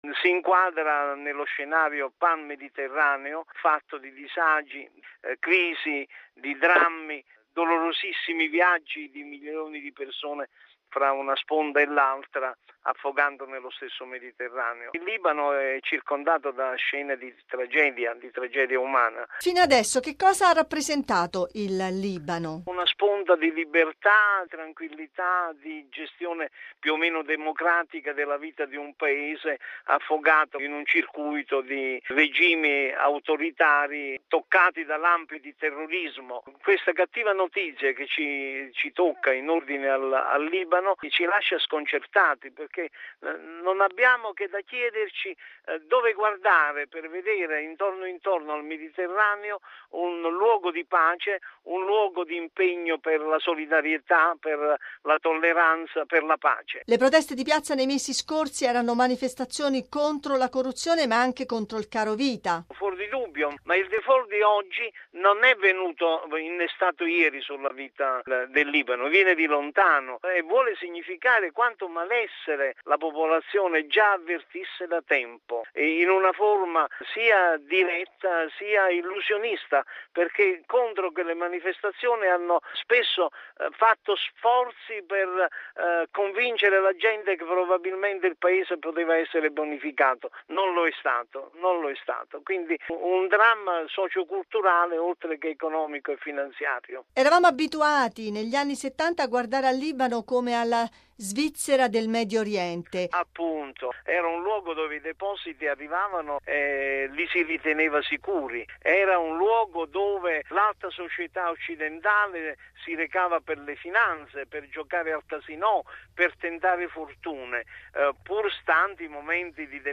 Per ricordare cosa rappresenti il Libano in tutta l’area mediorientale e per spiegare alcuni degli elementi alla base della crisi economica, abbiamo intervistato